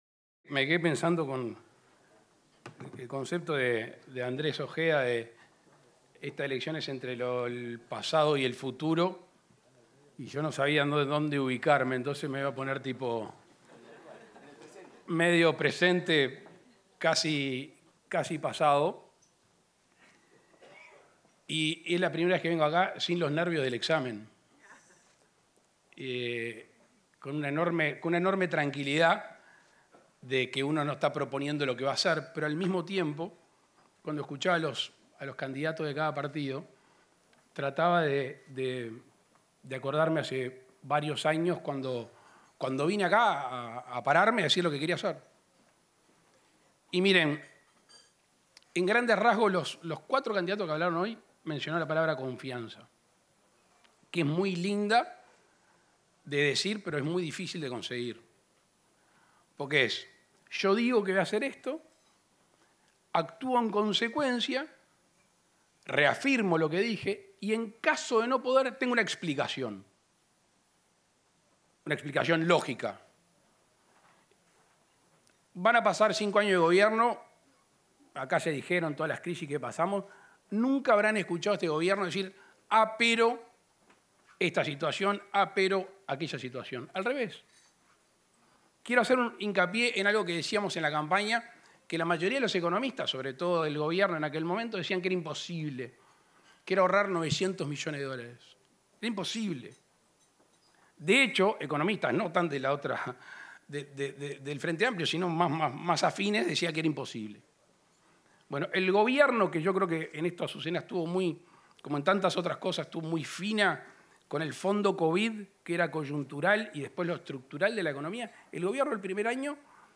Palabras del presidente de la República, Luis Lacalle Pou
Palabras del presidente de la República, Luis Lacalle Pou 30/07/2024 Compartir Facebook X Copiar enlace WhatsApp LinkedIn Durante un almuerzo de la Unión de Exportadores del Uruguay, para conmemorar el Día de la Exportación, este 30 de julio, se expresó el presidente de la República, Luis Lacalle Pou.